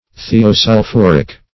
Search Result for " thiosulphuric" : The Collaborative International Dictionary of English v.0.48: Thiosulphuric \Thi`o*sul*phur"ic\, a. [Thio- + sulphuric.]